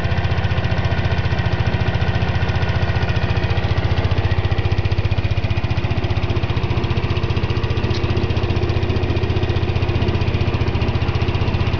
コマジェのアイドリング音です(^_^;)。